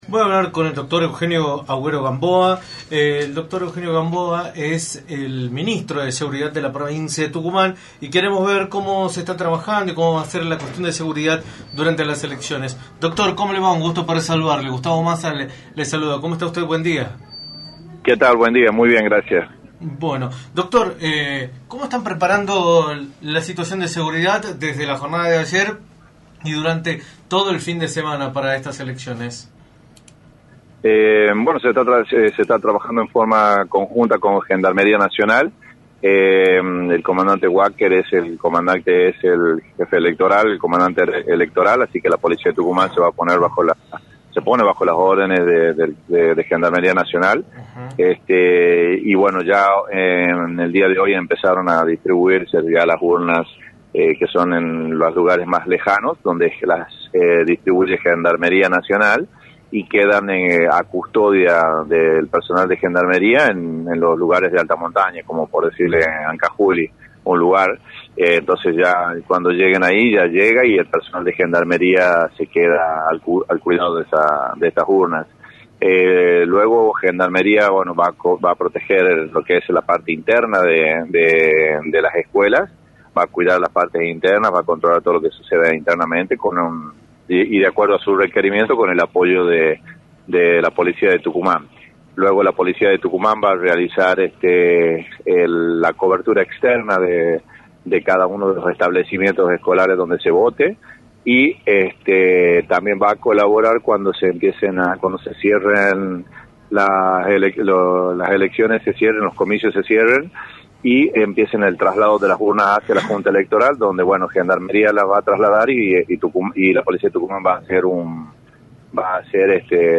“Se está trabajando de manera conjunta con Gendarmería Nacional, desde hoy se están distribuyendo las urnas a los lugares más lejanos a custodia del personal de Gendarmería, luego Gendarmería cuidará la parte interna de las escuelas y la Policía cuidará la parte externa de las instituciones y el traslado de las urnas” informó el Ministro de Seguridad en entrevista para “La Mañana del Plata”, por la 93.9.